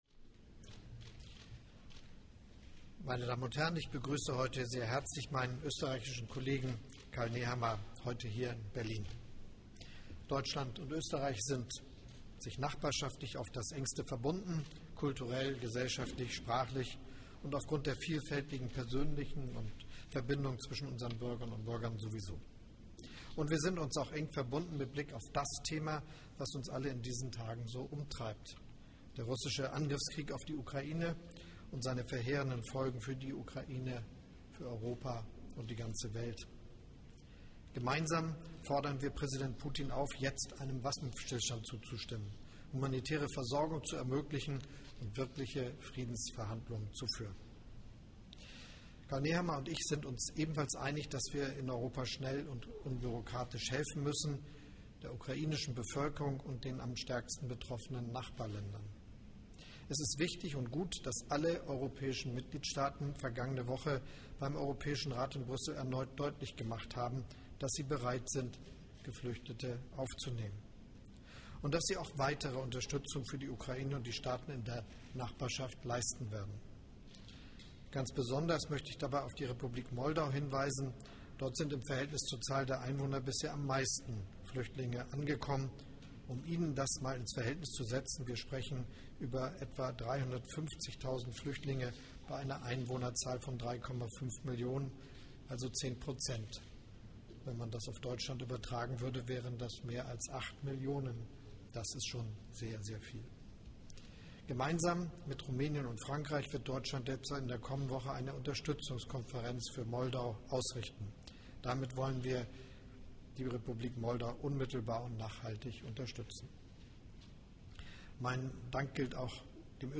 Pressekonferenz der Bundeskanzler
Bundeskanzler Scholz nach dem Treffen mit dem österreichischen Bundeskanzler Karl Nehammer.